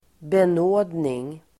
Uttal: [ben'å:dning]